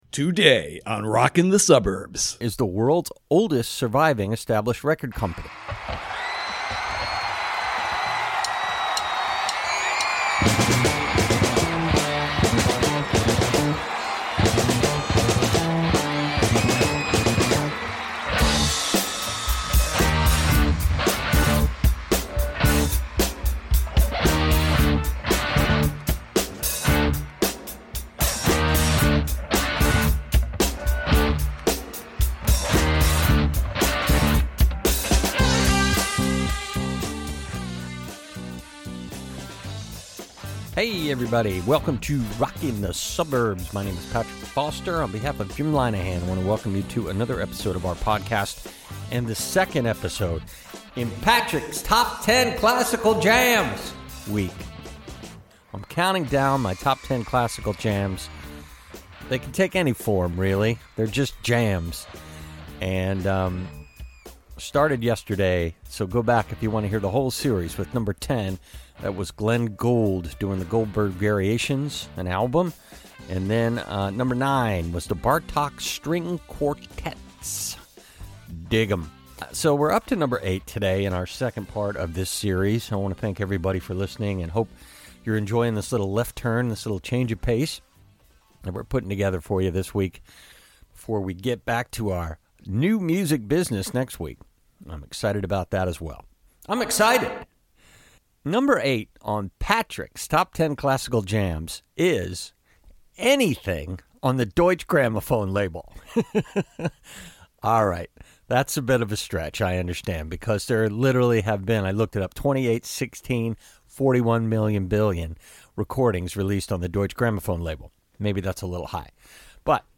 He’s about to tour in May and talks to us here from his house in the Bahamas – straw hat, roosters crowing! – looking back at the first gigs he ever saw and played and other delights su…